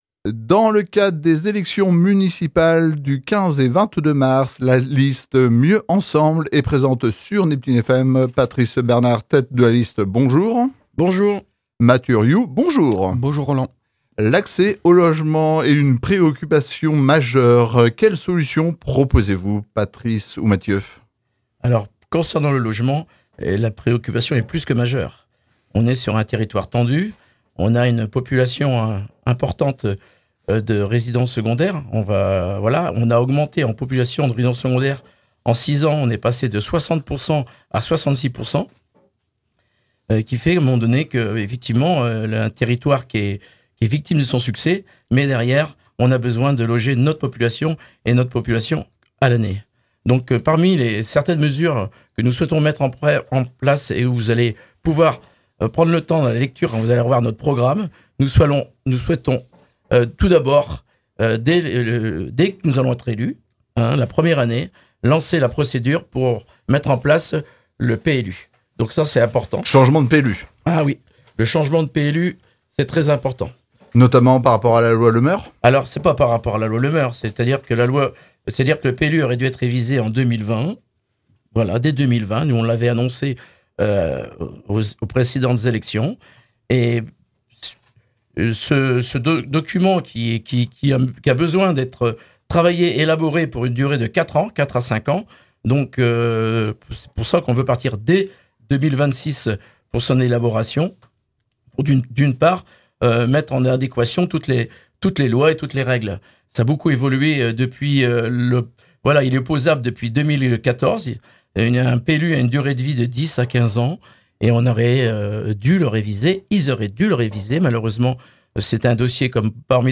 Cette émission a été enregistrée le mardi 24 février 2026 dans les conditions du direct, aucun montage n'a été effectué dans les propos des candidats.